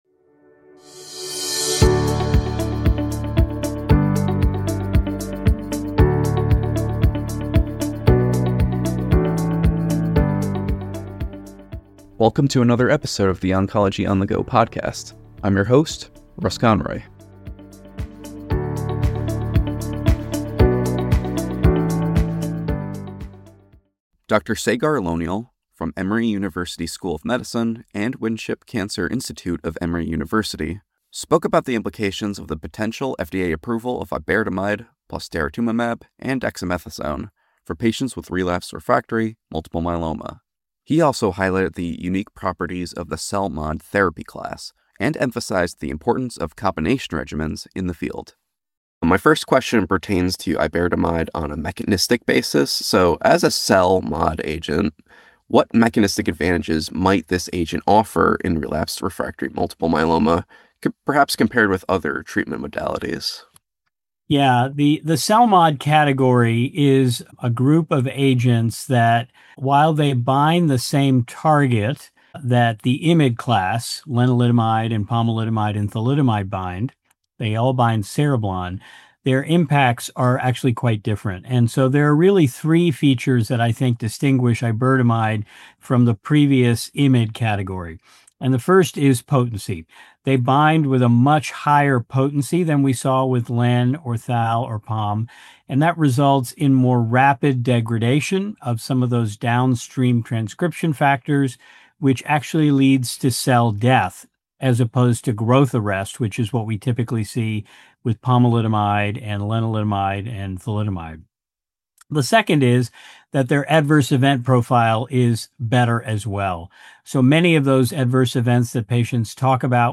In a conversation with CancerNetwork®